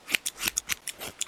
haircut3.wav